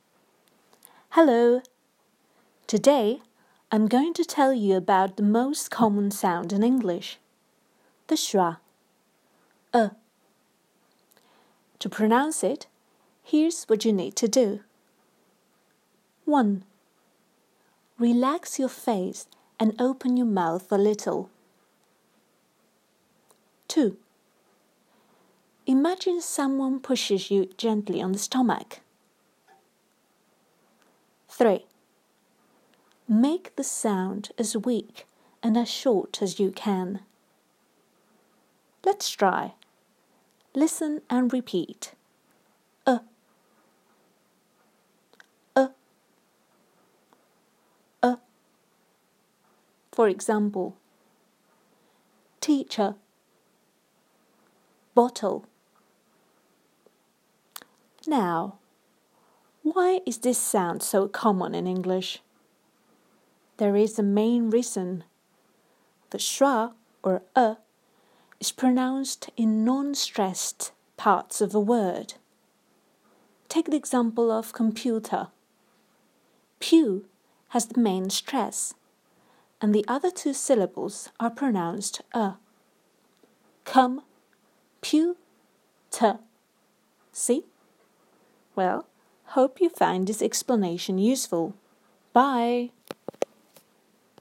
The ending –er is NOT stressed and is pronounced in a very particular way; what you have heard is the most common sound in English: /Ə/, also known as “the schwa”. Listen to the recording below and find out why /Ə/ is so common and how it is pronounced.